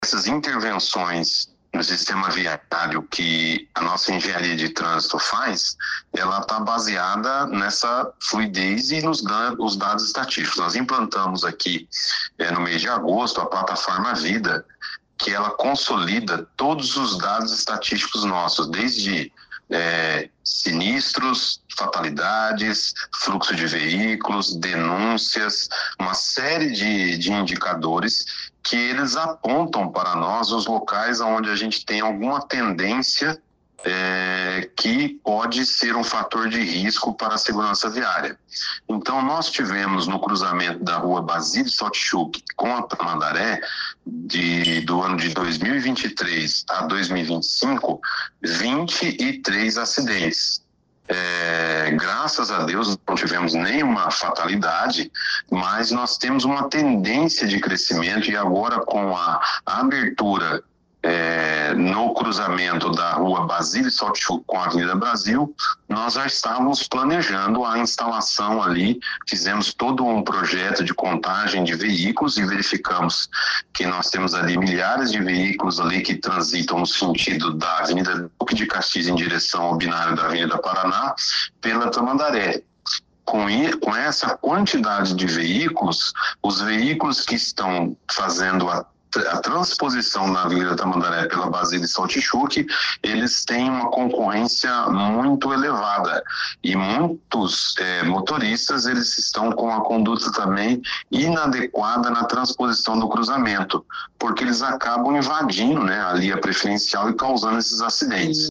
O secretário Luciano Brito disse que nos últimos três anos, houve registro de 23 acidentes nesse cruzamento.